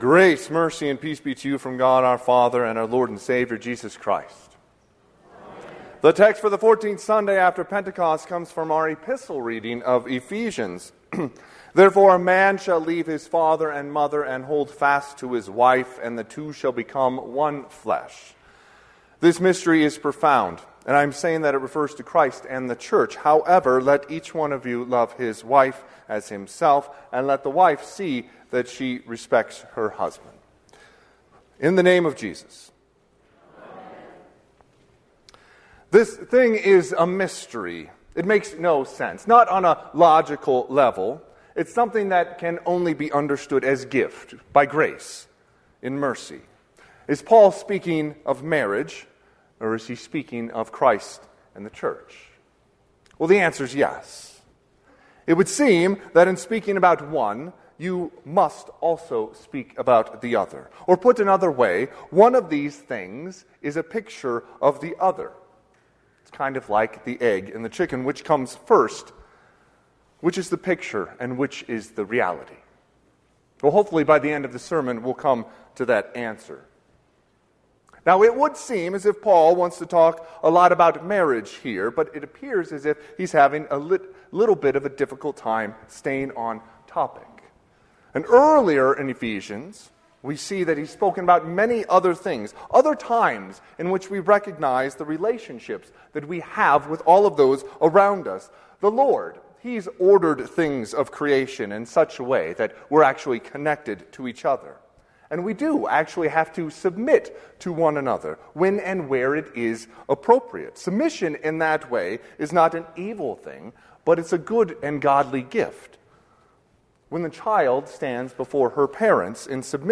Sermon - 8/25/2024 - Wheat Ridge Lutheran Church, Wheat Ridge, Colorado